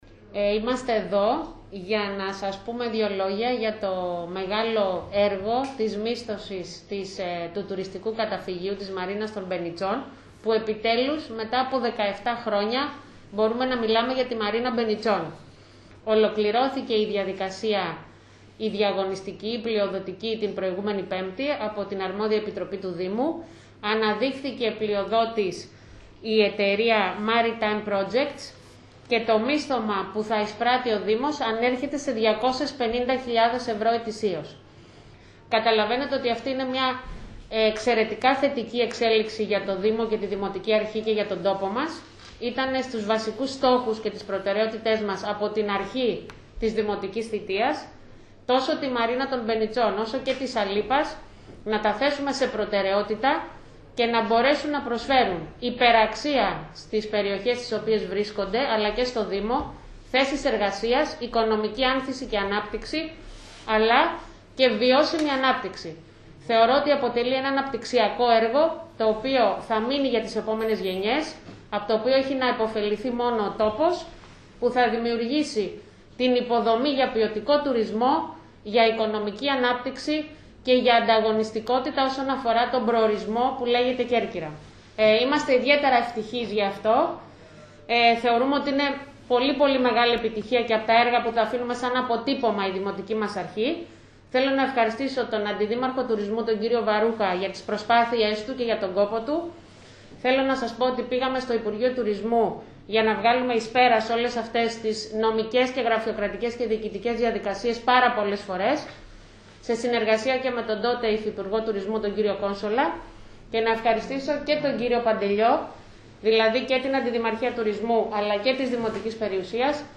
Αυτό ανακοίνωσε σήμερα η Δημοτική αρχή σε συνέντευξη τύπου, που έδωσαν η Δήμαρχος, Μερόπη Υδραίου και οι αντιδήμαρχοι Τουρισμού, Παναγιώτης Βαρούχας και Οικονομικών Γιώργος Παντελιός.